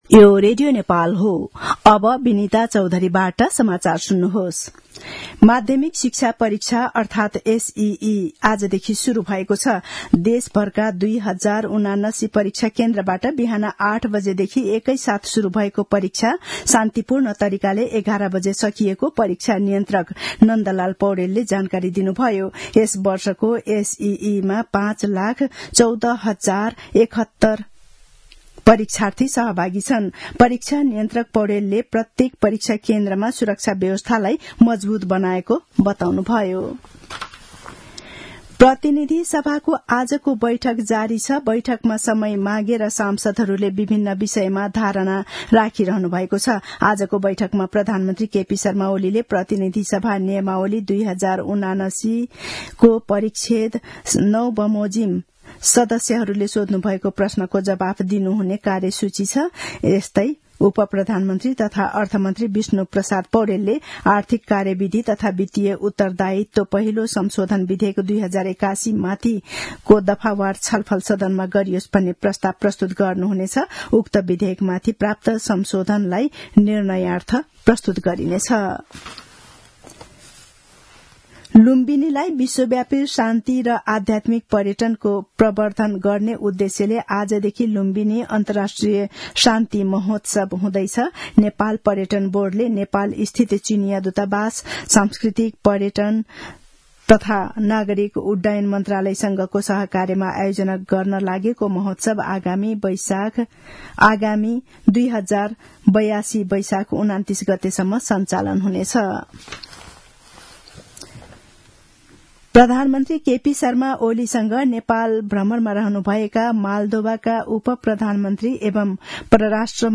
दिउँसो १ बजेको नेपाली समाचार : ७ चैत , २०८१
1-pm-news-1-5.mp3